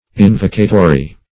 Meaning of invocatory. invocatory synonyms, pronunciation, spelling and more from Free Dictionary.
Invocatory \In"vo*ca*to*ry\, a.